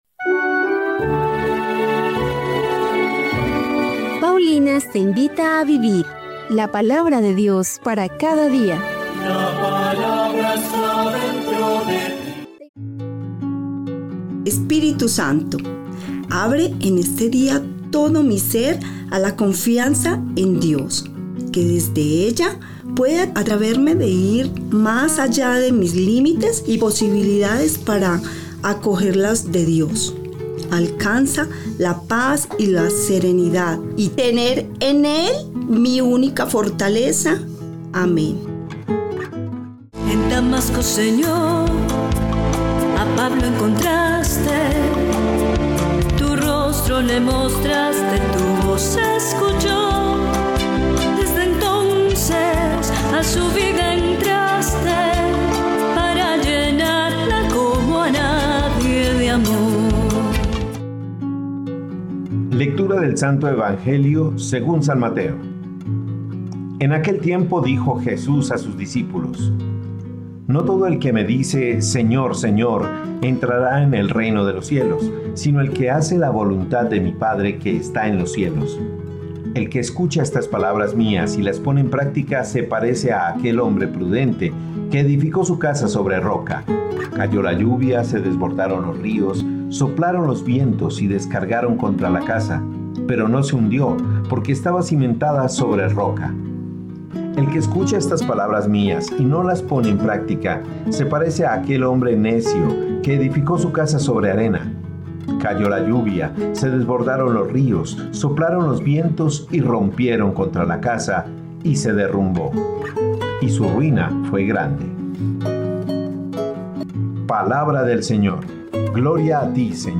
Liturgia diaria